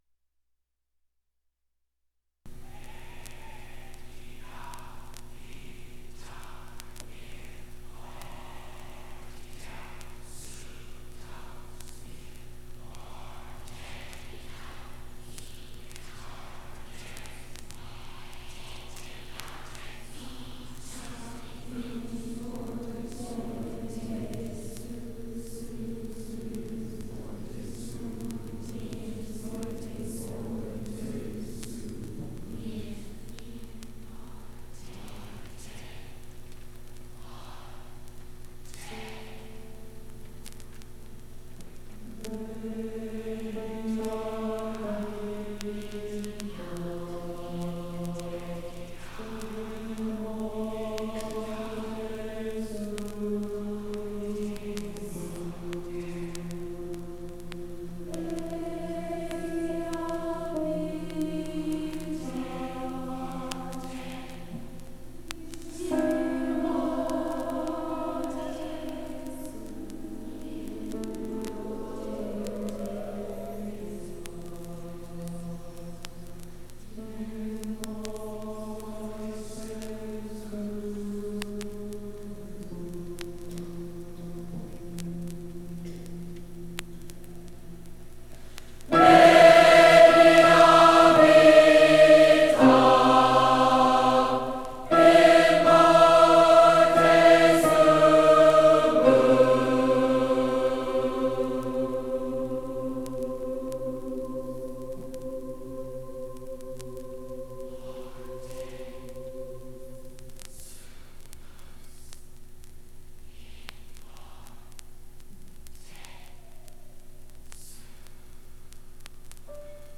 c18adea7cebbf740f45b93221e3c394e625246e6.mp3 Title 1975 Music in May chorus and band performance recording Description An audio recording of the 1975 Music in May chorus and band performance at Pacific University.
It brings outstanding high school music students together on the university campus for several days of lessons and events, culminating in the final concert that this recording preserves.